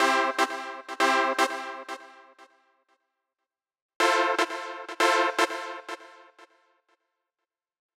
29 Synth PT4.wav